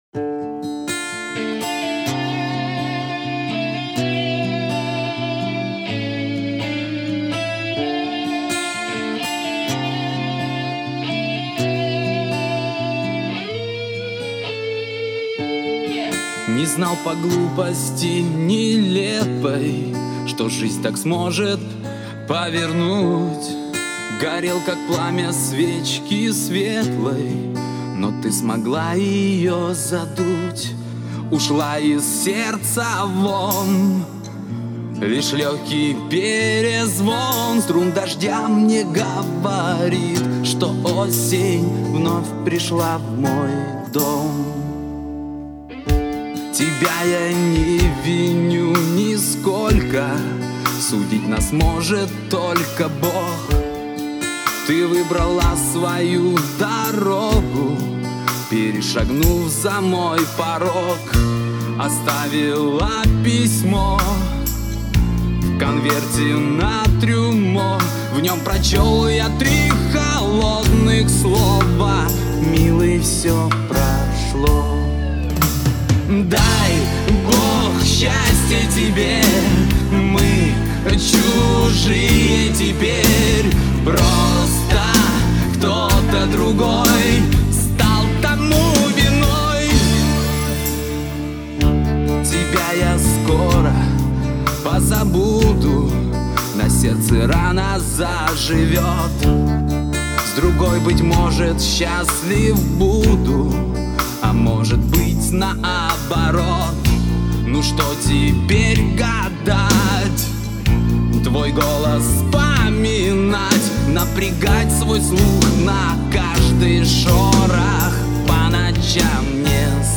грусть, печаль